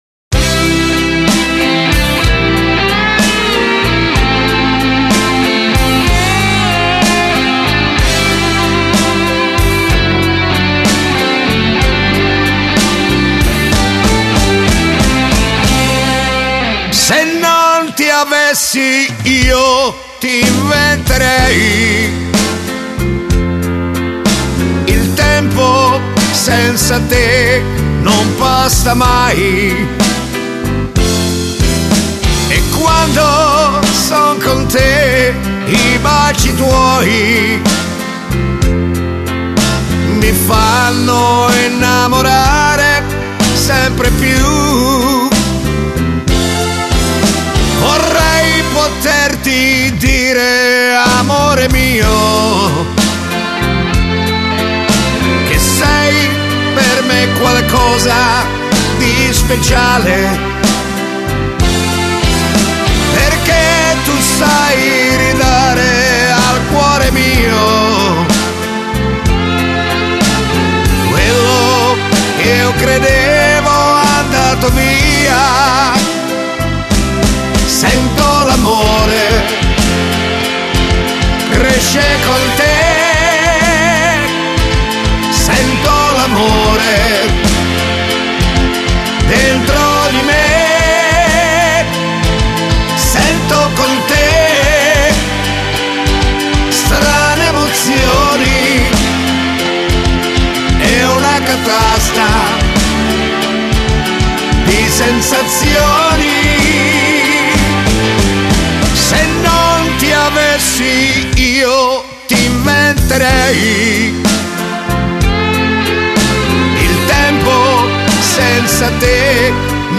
Genere: Terzinato